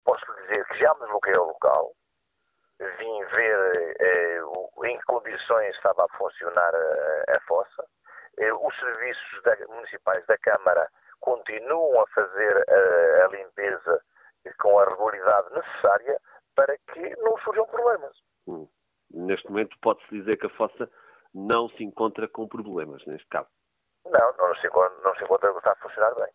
Em declarações à Alive FM, Francisco Carvalho, presidente da Câmara Municipal de Penalva do Castelo, mostrou-se surpreendido com a atitude do Partido Ecologista Os Verdes, e, sublinhou que não houve queixa da população referindo que a fossa tem deficiências, mas a autarquia tem feito limpeza assídua, evitando corrimento e para Francisco Carvalho a situação em causa tem a ver com águas pluviais e não com águas residuais.